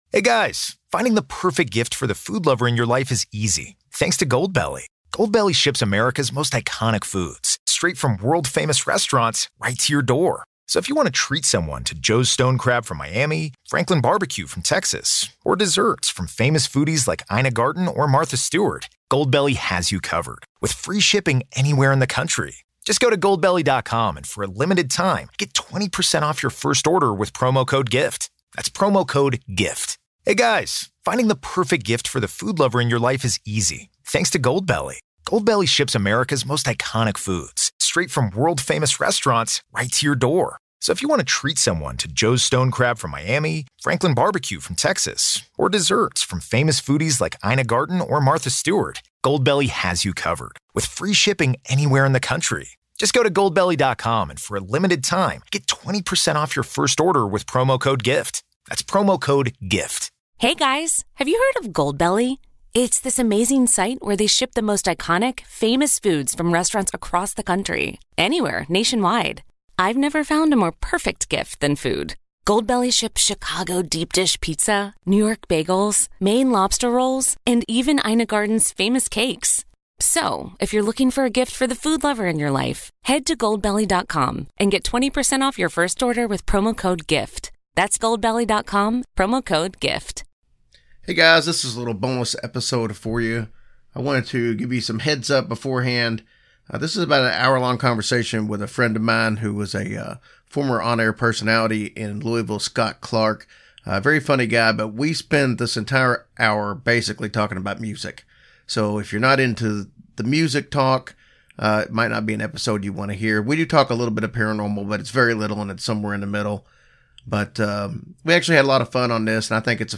There is a little bit of paranormal in our chat, but it is mostly about music. There is some cursing, so be aware.